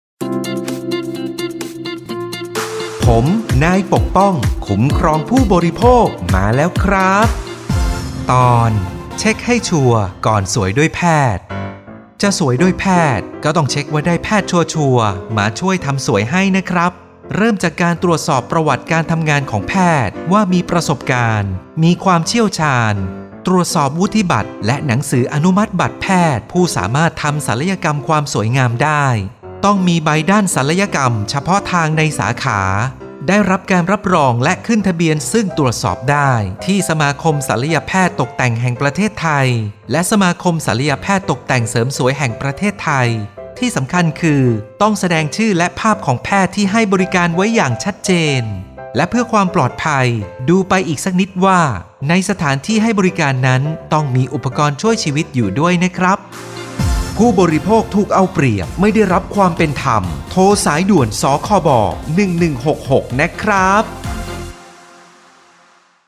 สื่อประชาสัมพันธ์ MP3สปอตวิทยุ ภาคกลาง
023.สปอตวิทยุ สคบ._ภาคกลาง_เรื่องที่ 23_.mp3